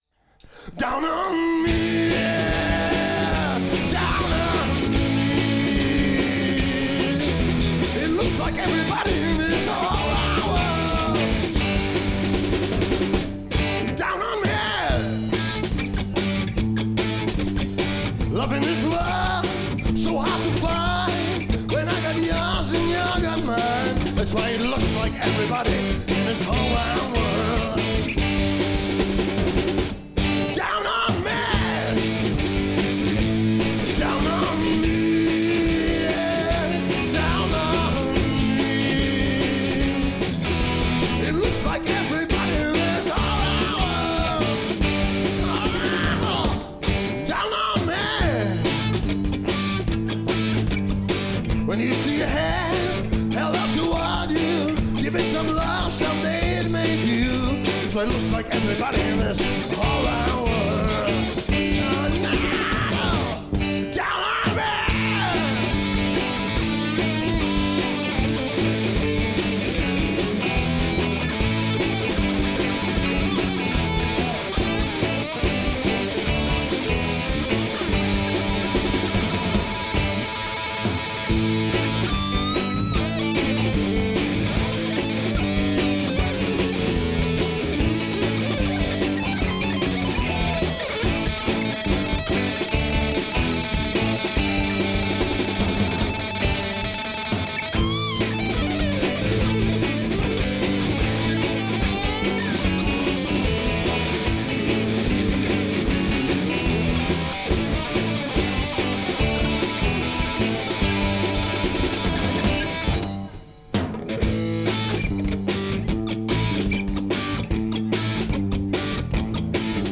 Stará Pekárna 26.8.2011,